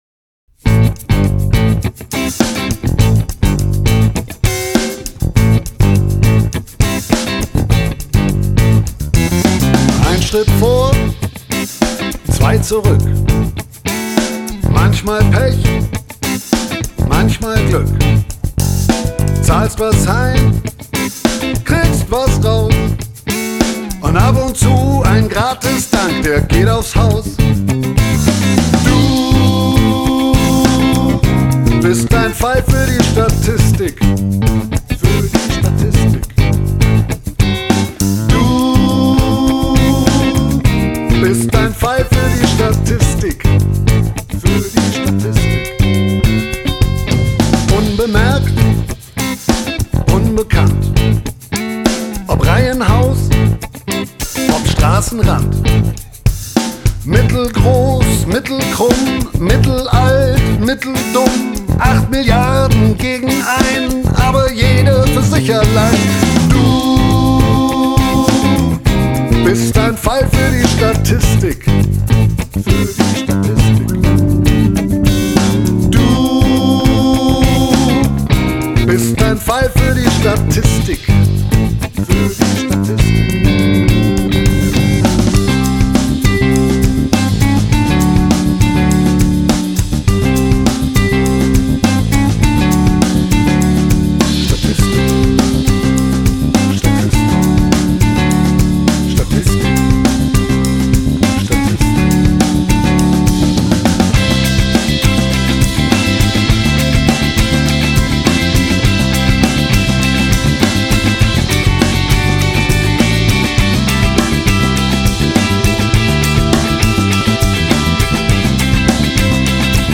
dicker Schlaghosen-Bass
elegante Soul-Grooves
lässige Surf-Gitarre